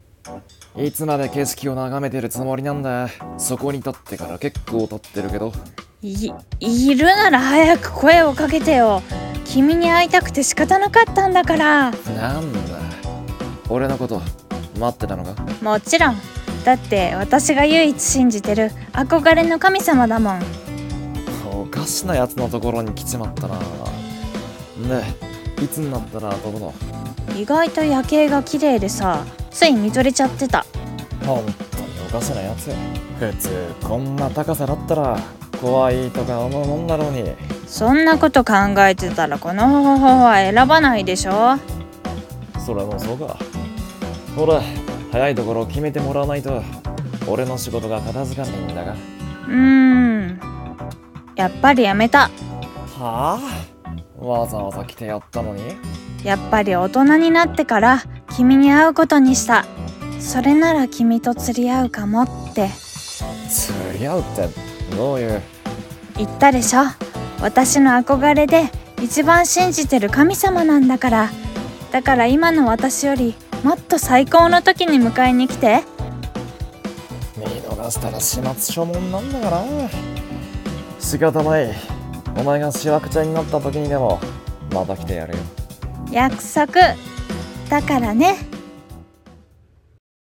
【二人声劇】神様との約束